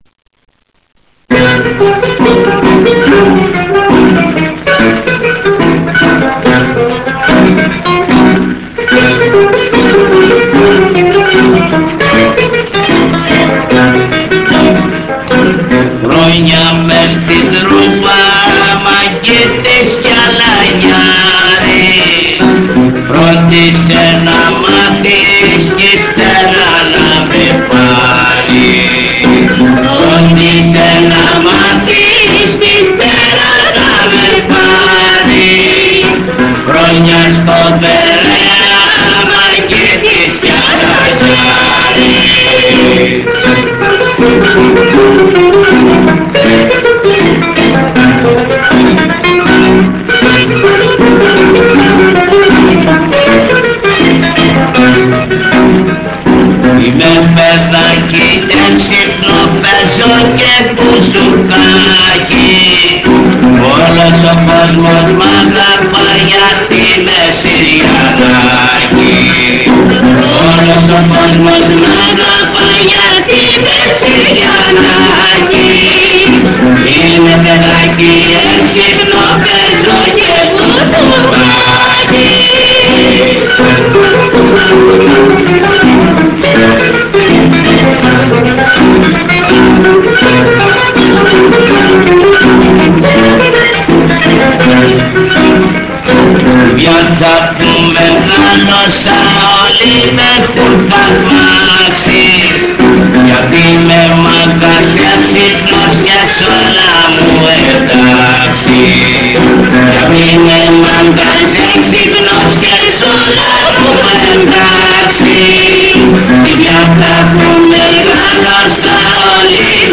Zeimbekiko